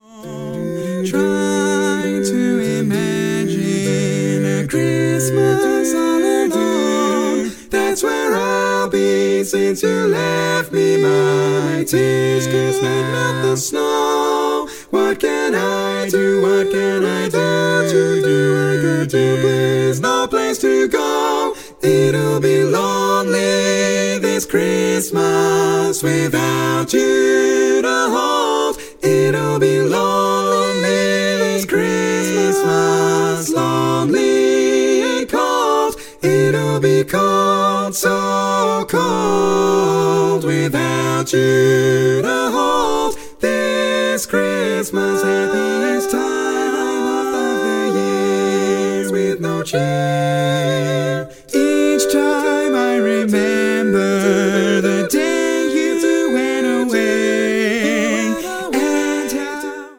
Full mix only
Category: Female